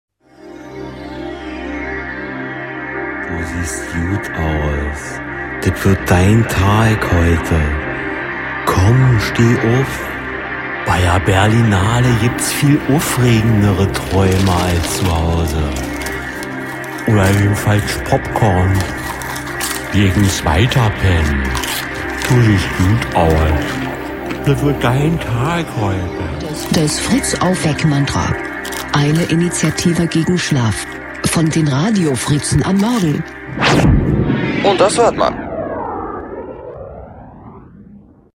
Fritz Aufweck-Mantra 09.02.17 (Berlinale) | Fritz Sound Meme Jingle
Die MP3 ist unbearbeitet und wurde ursprünglich von Fritz unter der CC-Lizenz hier veröffentlicht (Namensnennung, keine kommerzielle Nutzung, keine Bearbeitung).